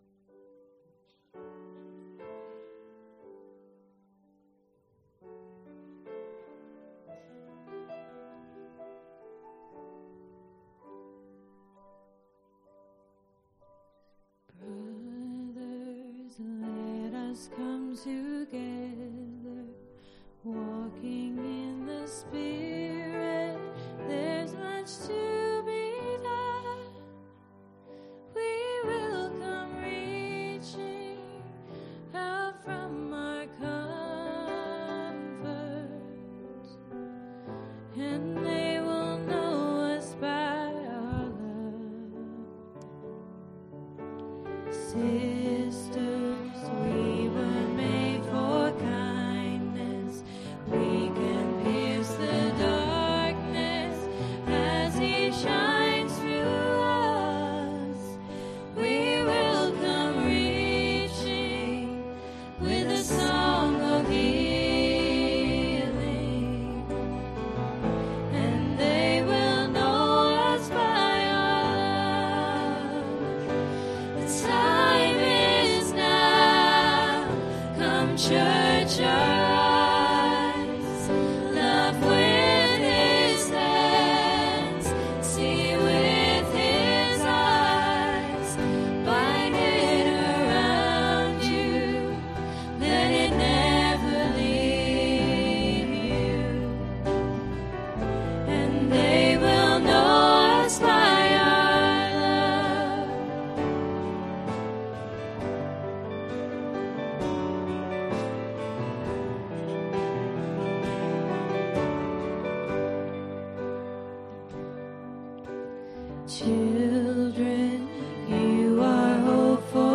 Ministry Song https
Service Type: pm